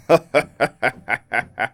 00545 man laugh 4 bad
bad evil laugh laughter man sound effect free sound royalty free Funny